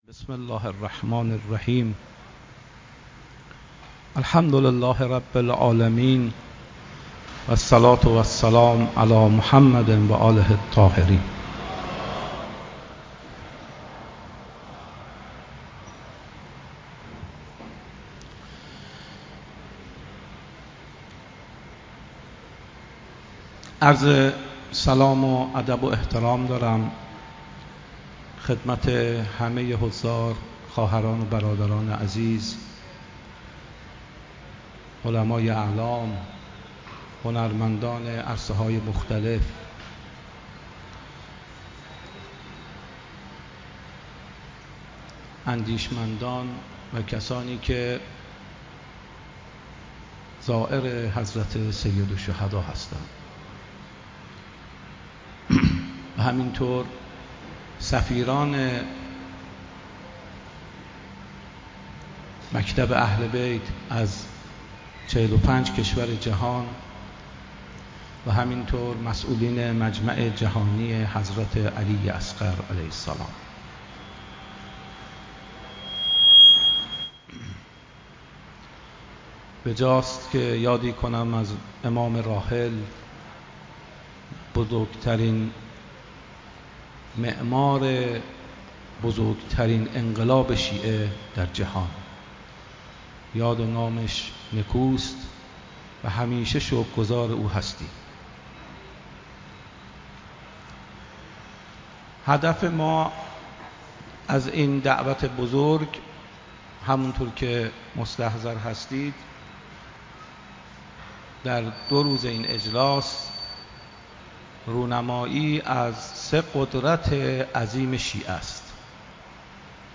This conference, attended by over 500 intellectuals and scholars from 45 nationalities, along with leading figures in the fields of art and media, was held over two days in Karbala, beside the holy shrine of Sayyid al-Shuhada (peace be upon him), on the 14th and 15th of Khordad 1403. The conference aimed to highlight the cloak of Imam Husayn (peace be upon him) as the symbol of Ashura and the banner of the uprising of the savior of humanity, as well as its role in contemporary times.